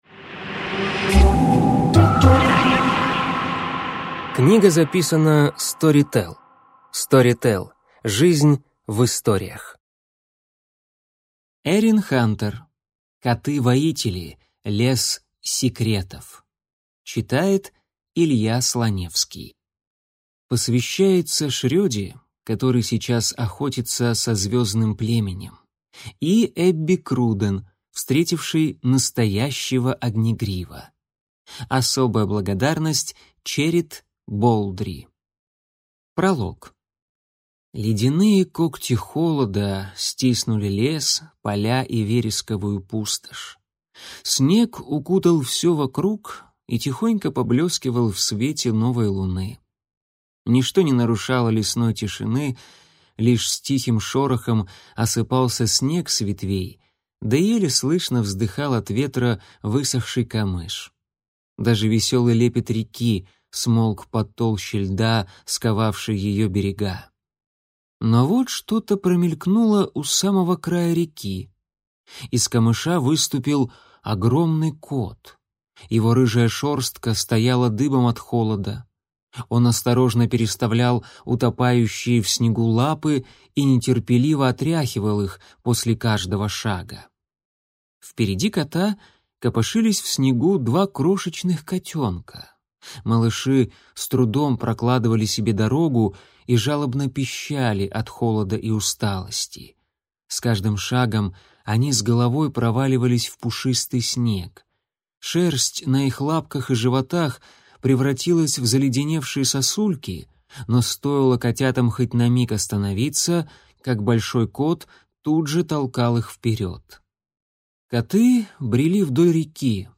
Аудиокнига Лес секретов | Библиотека аудиокниг